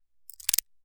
Part_Assembly_40.wav